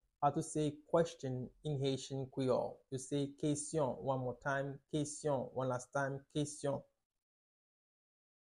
12.How-to-say-Question-in-Haitian-Creole-–-Keyson-pronunciation.mp3